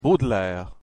Правильное произношение: Бодлер (с ударением на первый слог)
• Baudelaire — Бодлер